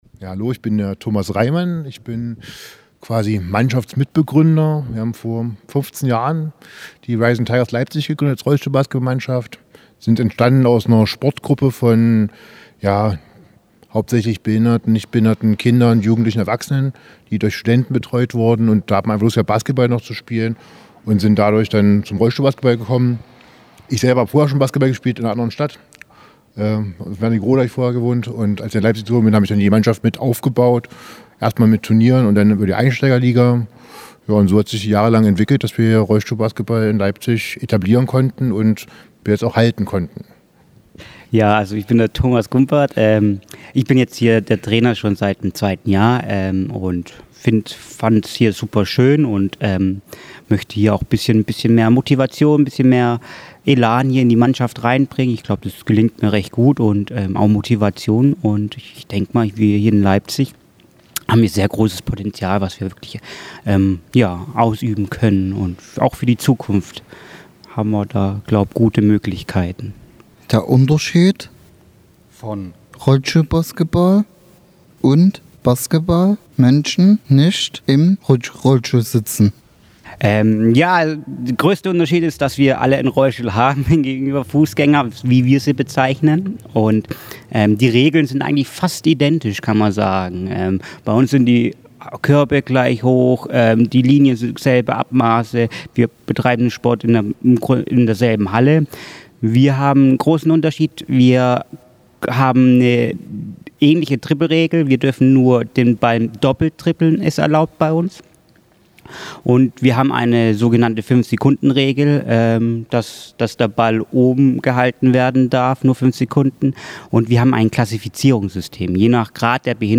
Interview mit Team vom Roll-Stuhl-Basketball “Rising Tigers” – Radio Inklusive